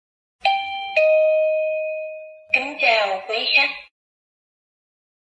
Âm thanh Chuông Báo Kính Chào Quý Khách! (Mẫu số 2)
Thể loại: Tiếng chuông, còi
am-thanh-chuong-bao-kinh-chao-quy-khach-mau-so-2-www_tiengdong_com.mp3